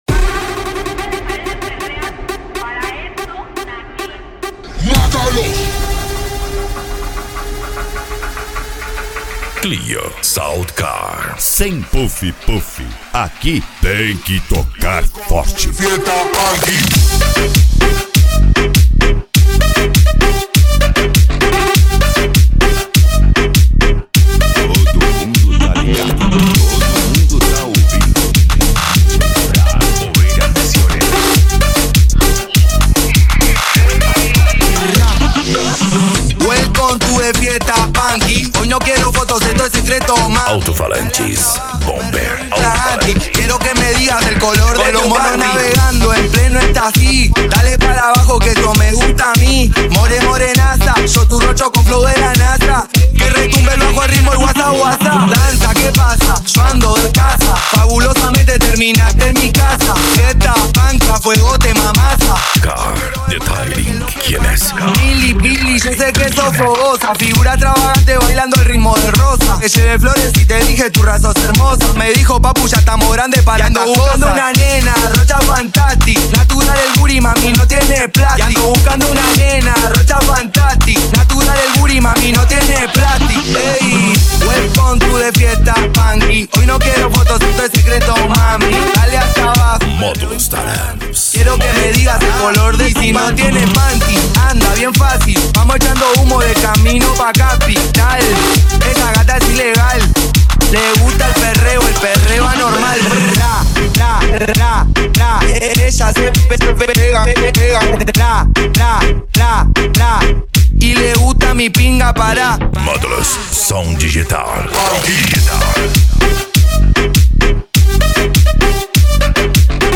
Bass
Eletronica
Modao
Remix